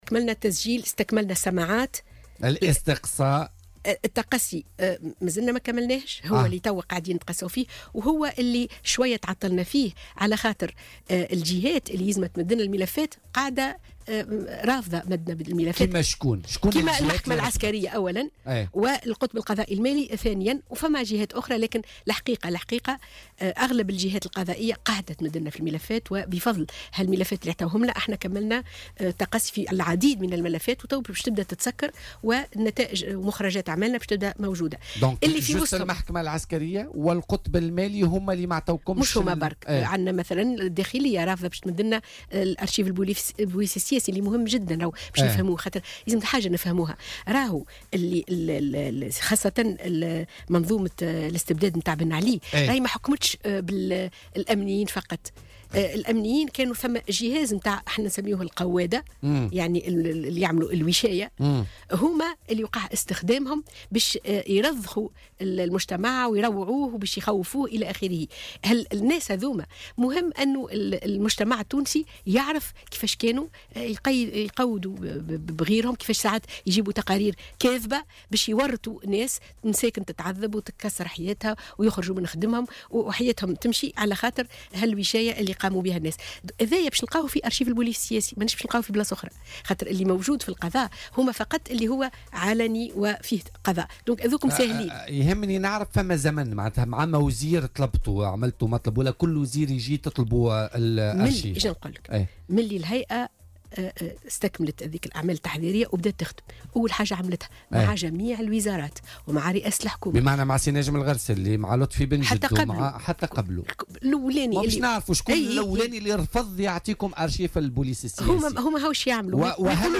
وأضافت اليوم خلال مداخلة لها في برنامج "بوليتيكا" أن بعض الجهات رفضت مد الهيئة بالملفات، مما تسبب في تعطيل عمليات التقصي.